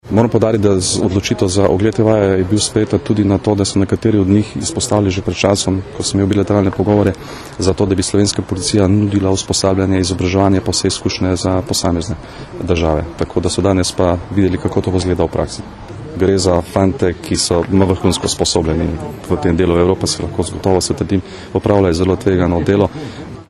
Zvočni posnetek izjave generalnega direktorja policije Janka Gorška o današnji prikazni vaji